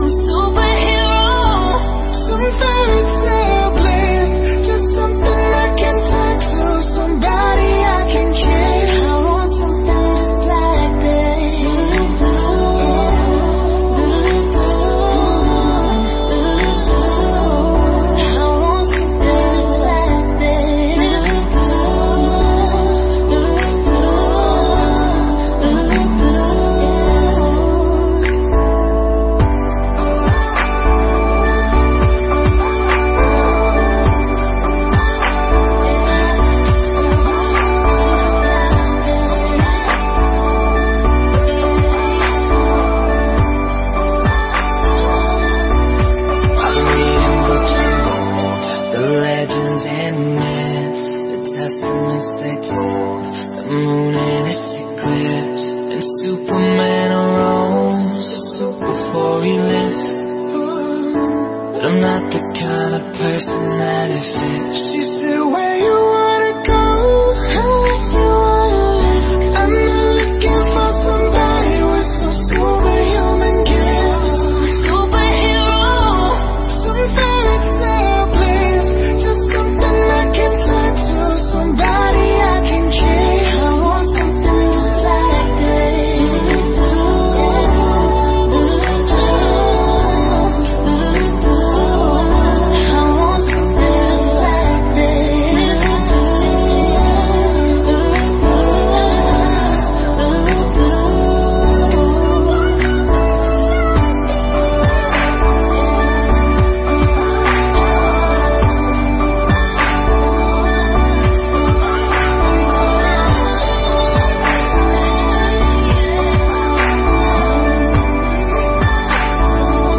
Nhạc Latin